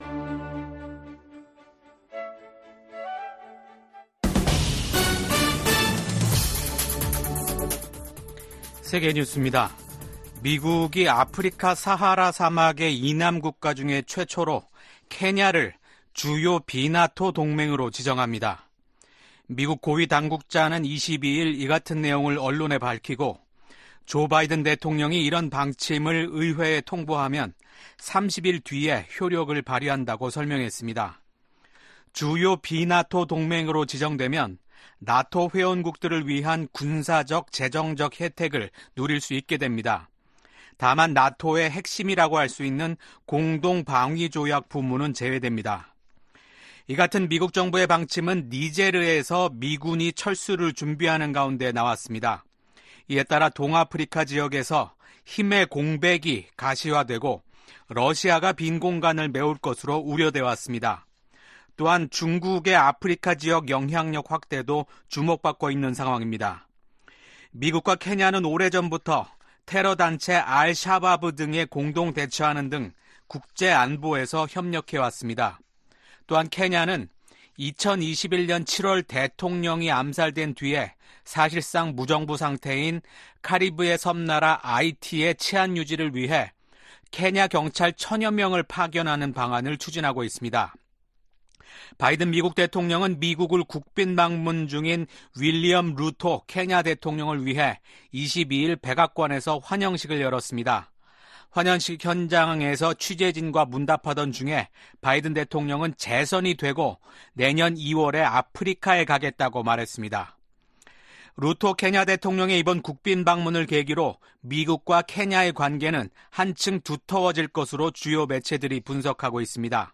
VOA 한국어 아침 뉴스 프로그램 '워싱턴 뉴스 광장' 2024년 5월 24일 방송입니다. 토니 블링컨 국무장관은, 미국이 한국, 일본과 전례 없는 방식으로 공조하고 있다고 하원 청문회에서 증언했습니다.